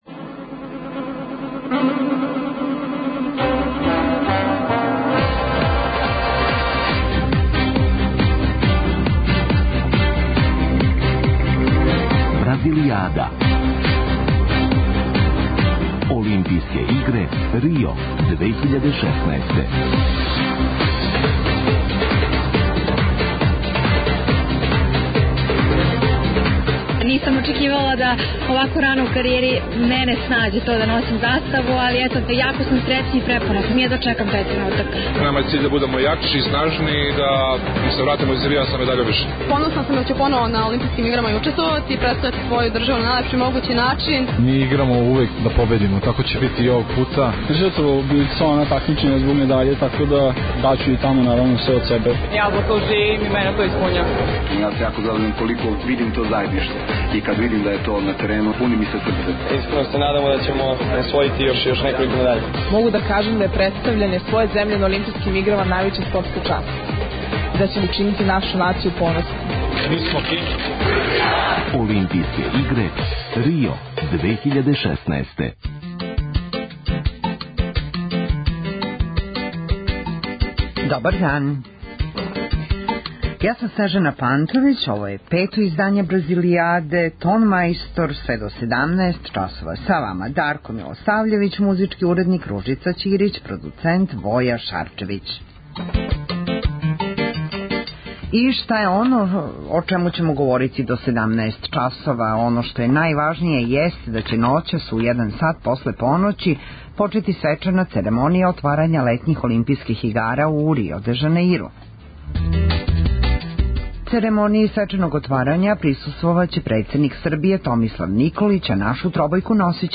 Специјална емисија која ће се емитовати током трајања Олимпијских игара у Рију. Пратимо наше спортисте који учествују на ОИ, анализирамо мечеве, уз госте у Студију 1 Радио Београда и укључења наших репортера са лица места.